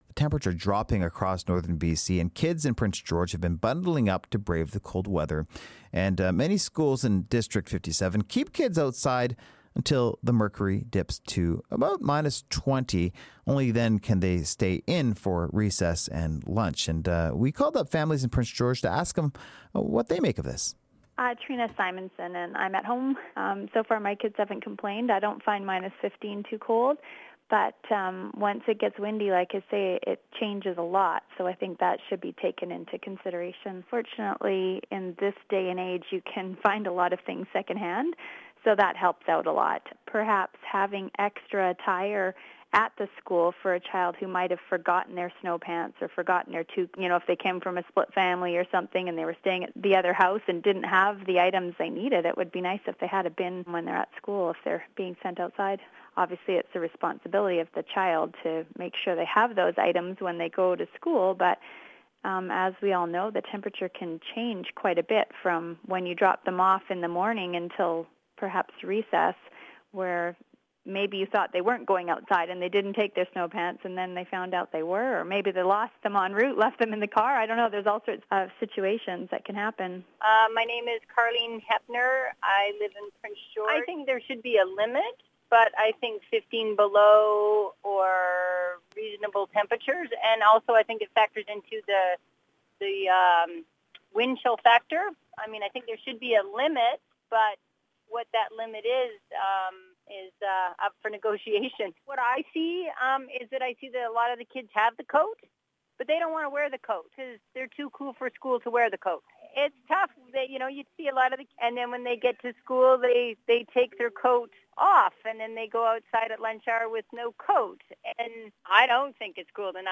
We ask parents in Prince George when they think the temperature is low enough that kids should stay inside at recess.